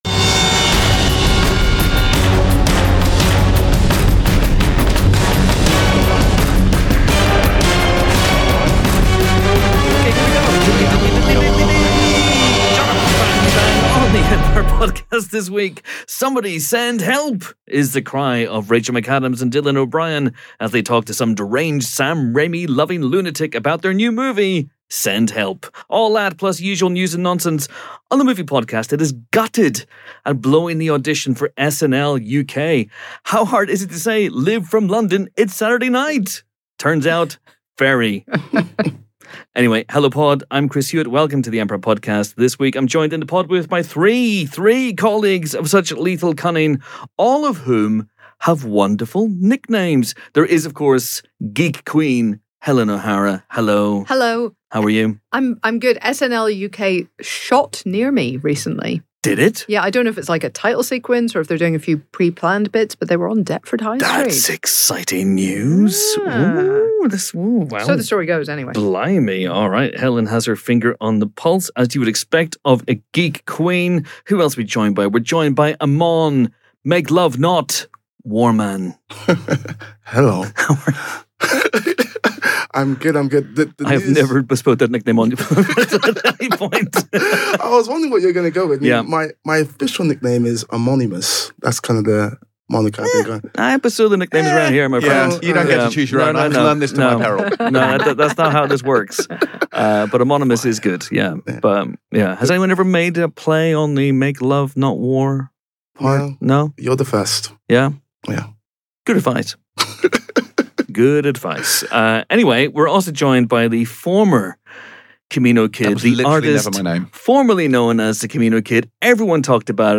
sit down for a delightfully dotty chat